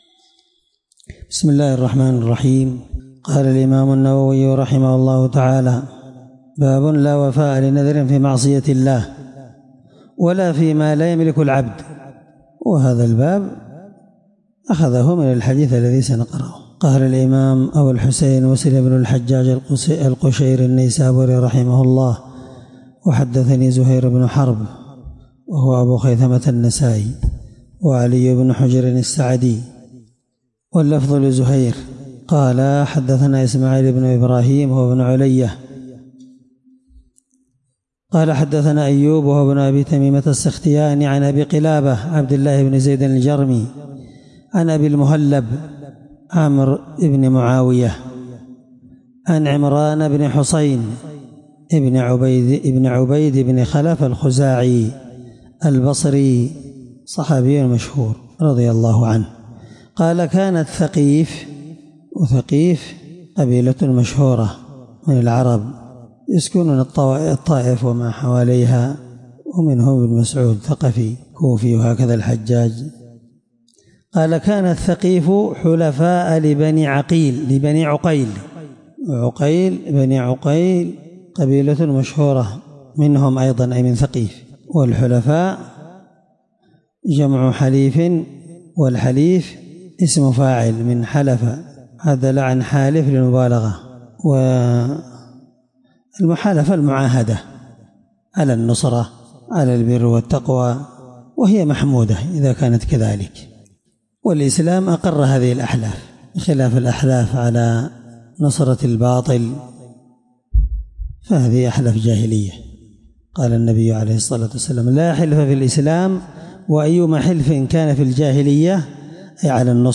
الدرس3من شرح كتاب النذر حديث رقم(1641) من صحيح مسلم
الدرس3من_شرح_كتاب_النذر_حديث_رقم(1641)_من_صحيح_مسلم.mp3